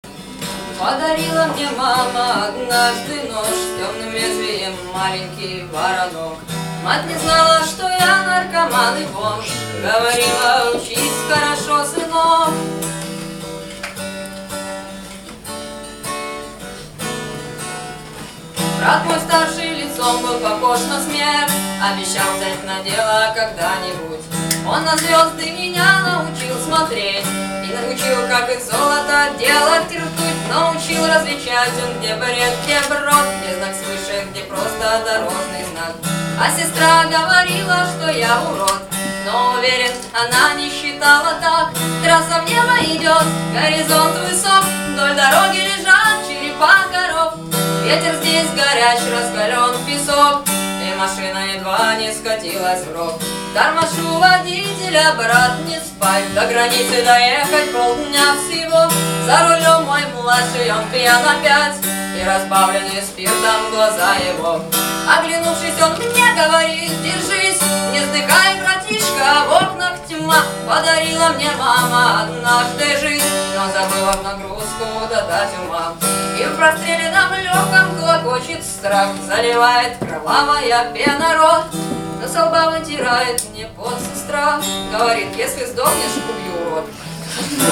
Гитара / Дворовые